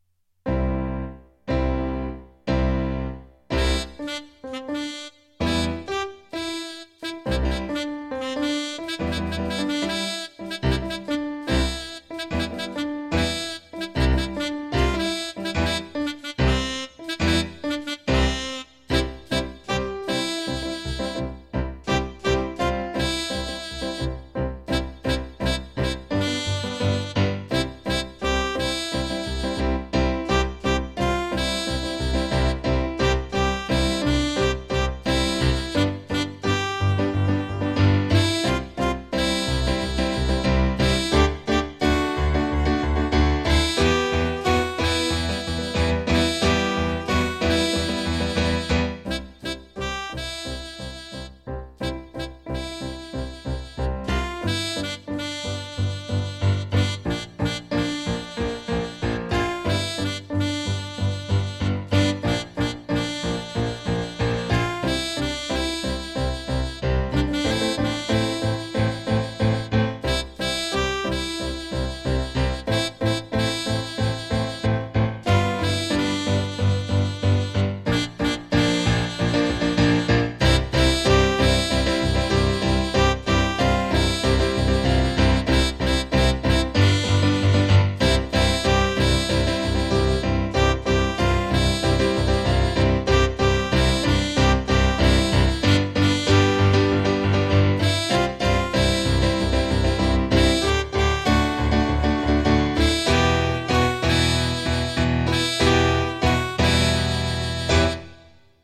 Just like in class, every recording will start with three piano chords to get ready before the melody starts and you can sing along (or simply follow along reading the score).  I used a different “instrument” from my keyboard’s sound library for each melody.